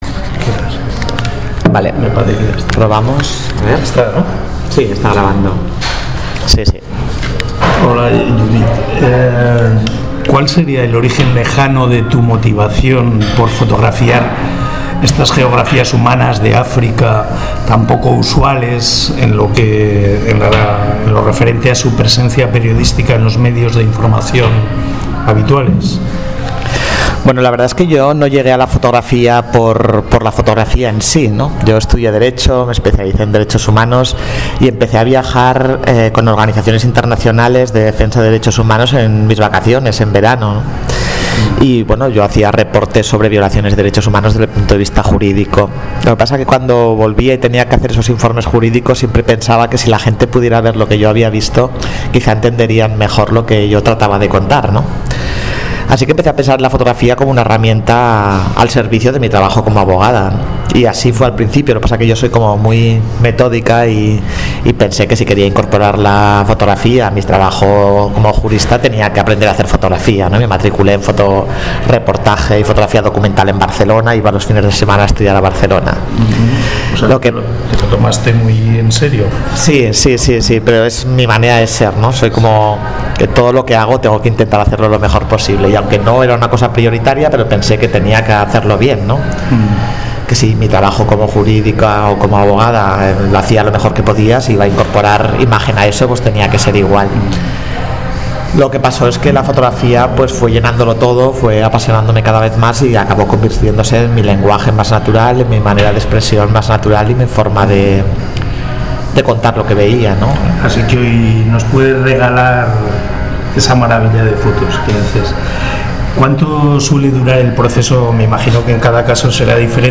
Este es un programa de radio que tiene por objeto informar sobre África en todos sus aspectos, Política, Sociedad, Cultura, Música…